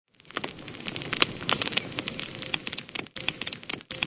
Hintergrundgeräusche und ein Hörspiel auf der CD: "Unsere Schmalfilme"